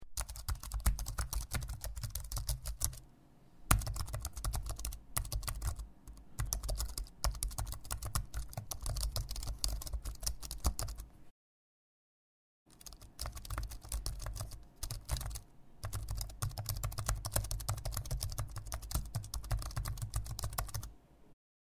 Звуки офиса
Окунитесь в атмосферу рабочего пространства с подборкой звуков офиса: стук клавиатуры, гул разговоров, звонки телефонов и другие привычные шумы.